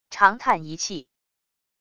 长叹一气wav音频